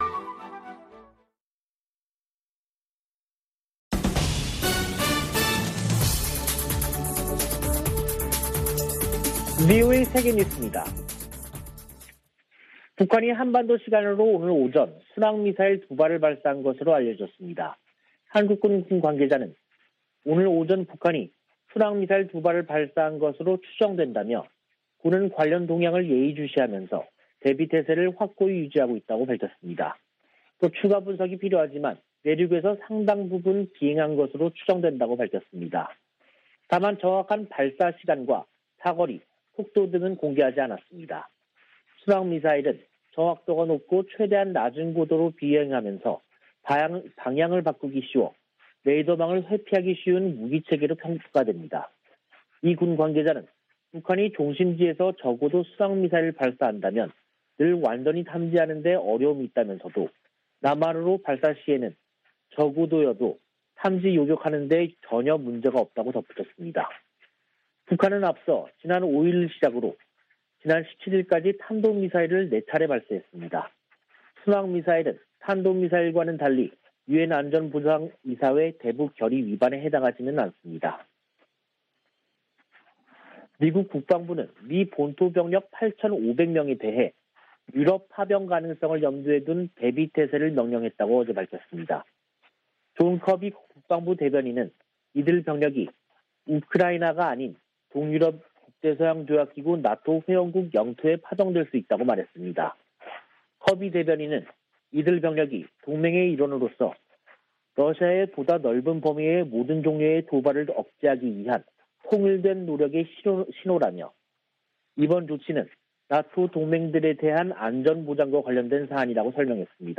VOA 한국어 간판 뉴스 프로그램 '뉴스 투데이', 2022년 1월 25일 2부 방송입니다. 북한이 순항미사일로 추정되는 발사체 2발을 쏜 것으로 전해졌습니다.